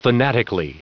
Prononciation du mot fanatically en anglais (fichier audio)
Prononciation du mot : fanatically